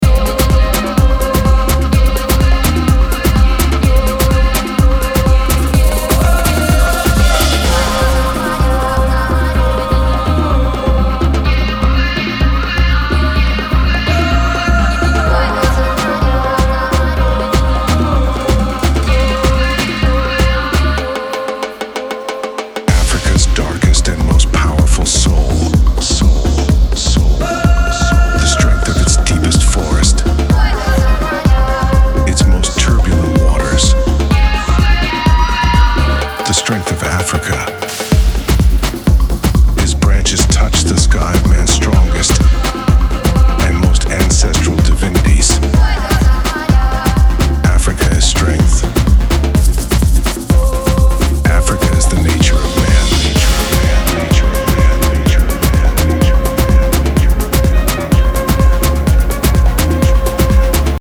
• Afro House